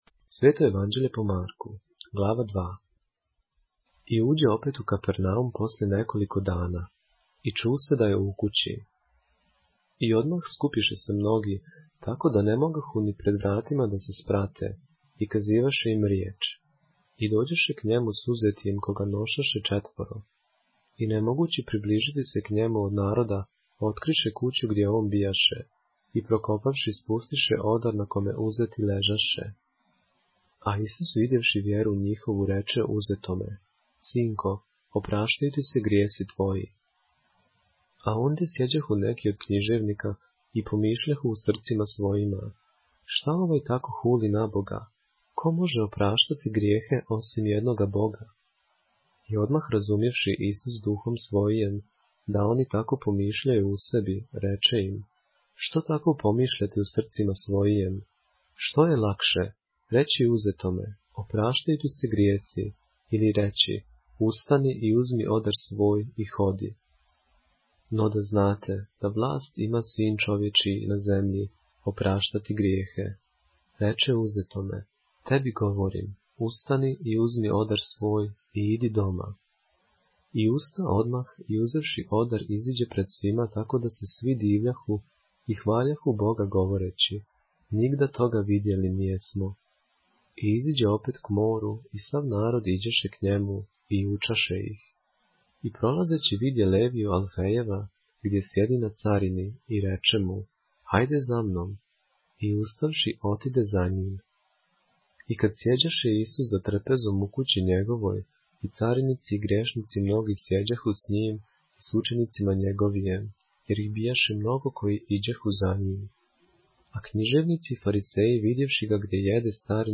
поглавље српске Библије - са аудио нарације - Mark, chapter 2 of the Holy Bible in the Serbian language